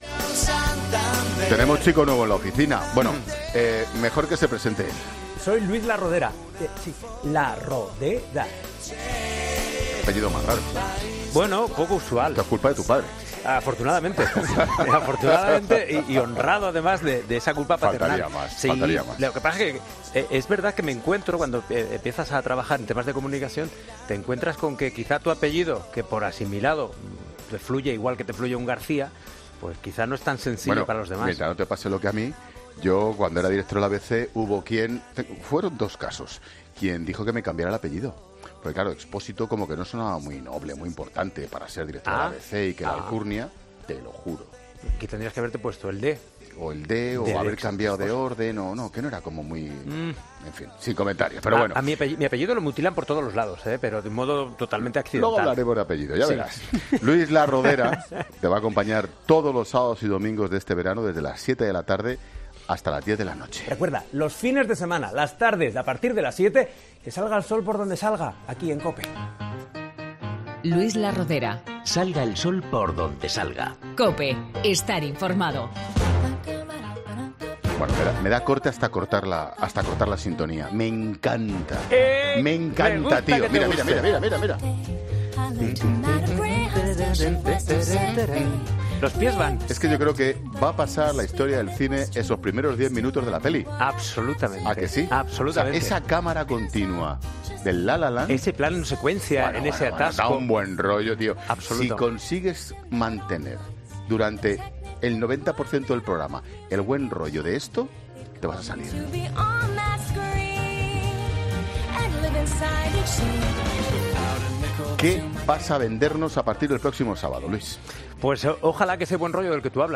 ESCUCHA LA ENTREVISTA COMPLETA | Luis Larrodera en 'La Tarde'